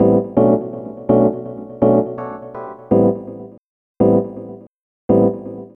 Index of /90_sSampleCDs/Zero-G - Total Drum Bass/Instruments - 2/track46 (Keyboards)
08 Steppen 170 B.wav